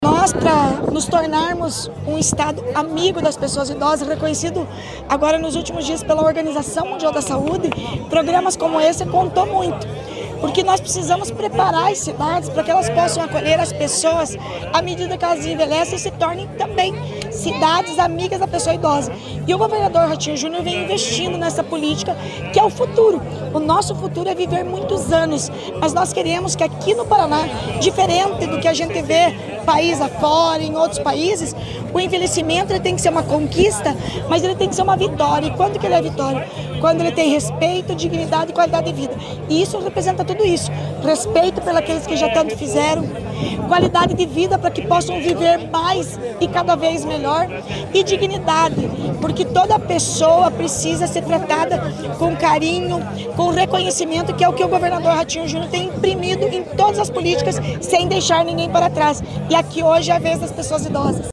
Sonora da secretária da Mulher, Igualdade Racial e Pessoa Idosa, Leandre Dal Ponte, sobre a entrega do Condomínio do Idoso de Arapongas